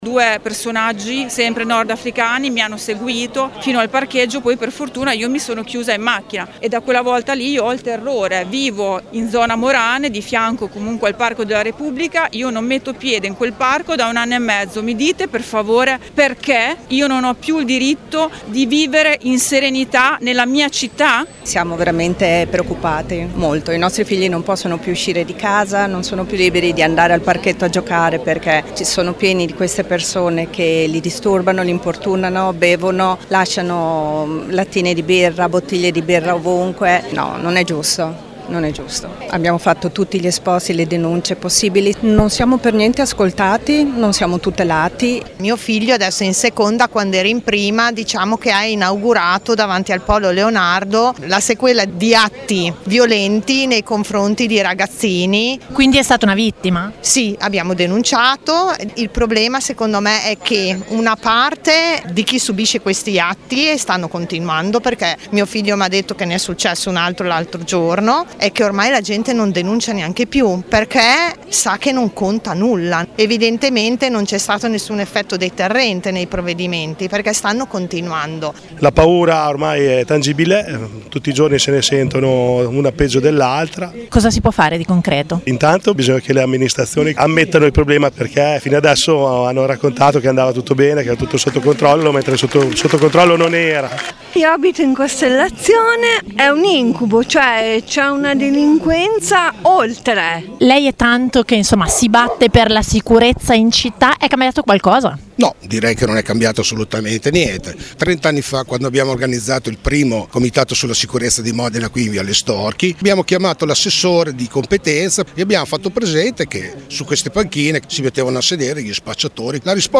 C’erano circa 200 persone ieri pomeriggio in autostazione a Modena al presidio organizzato dal comitato Modena merita di più per chiedere maggiore sicurezza in città: presenti tante donne, ma anche tanti genitori e ragazzi stanchi delle troppe aggressioni che avvengono davanti alle scuole.
vox-presidio-modena.mp3